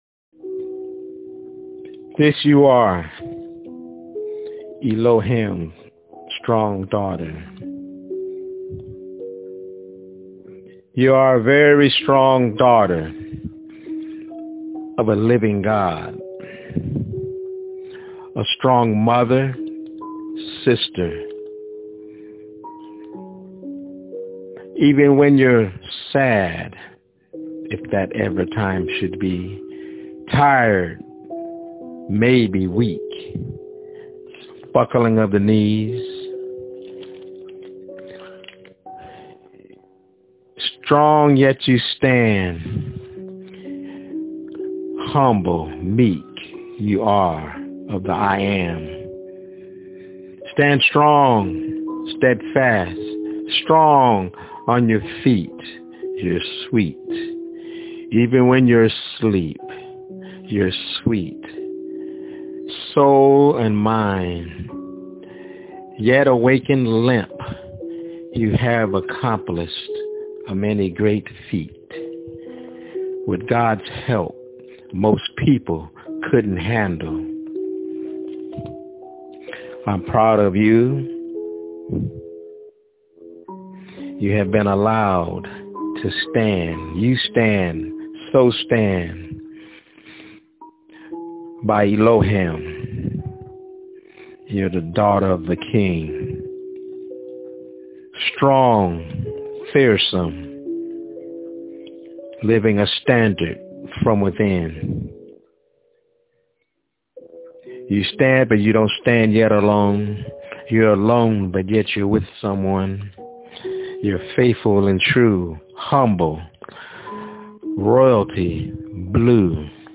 Elohim Strong Daughter- My Spokenword-